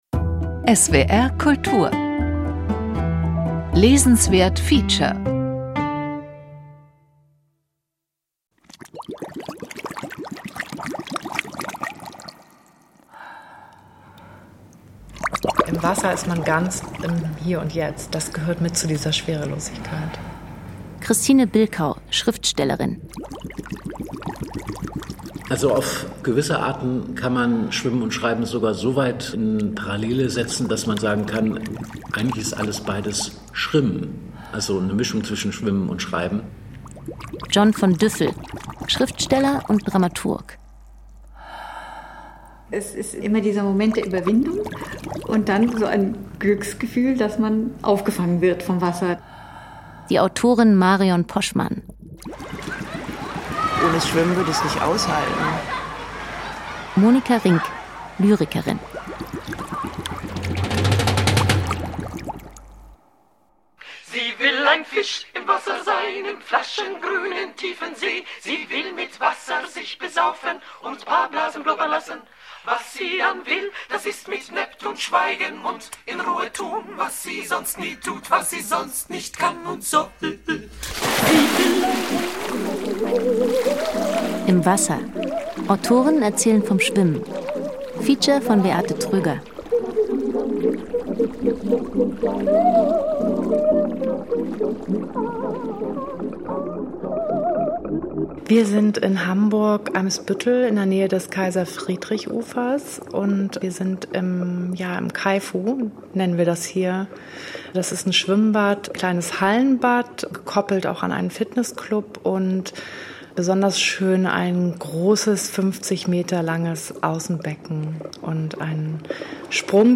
Eisbaden und Sommerschwimmen: Vier Autorinnen und Autoren erzählen, wie ihre Leidenschaft fürs Schwimmen das Schreiben beeinflusst.